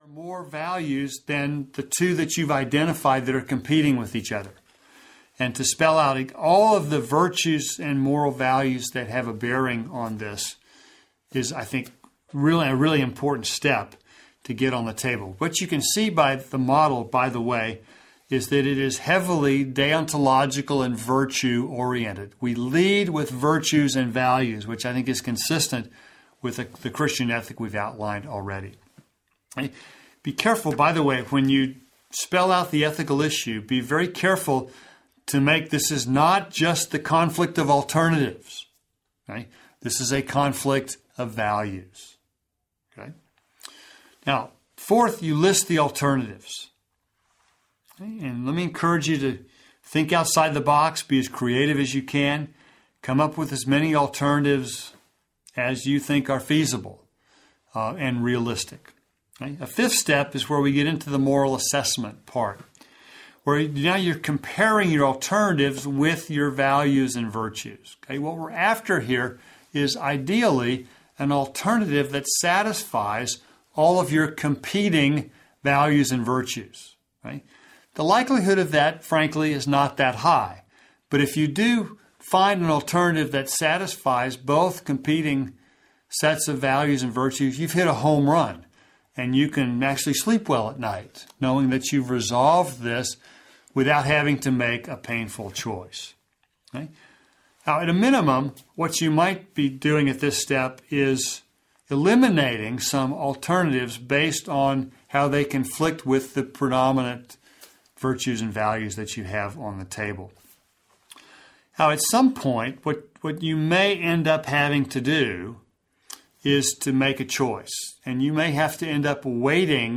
Moral Choices: Audio Lectures Audiobook